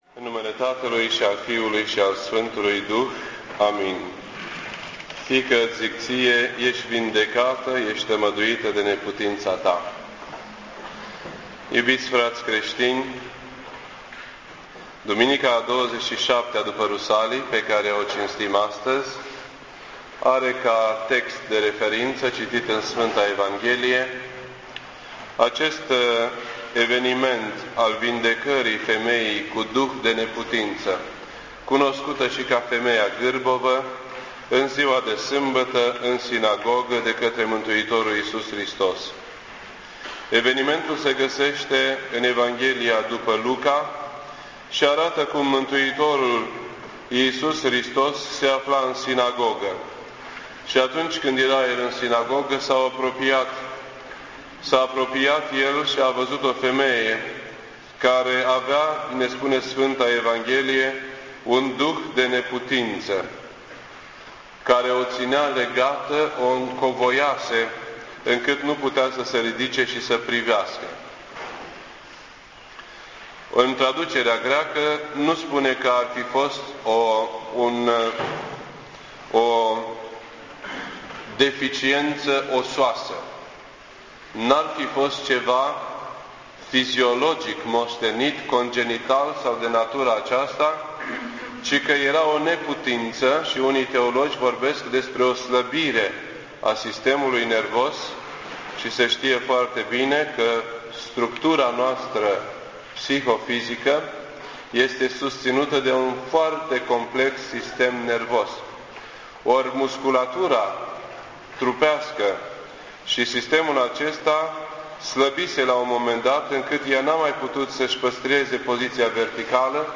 This entry was posted on Sunday, December 7th, 2008 at 9:23 AM and is filed under Predici ortodoxe in format audio.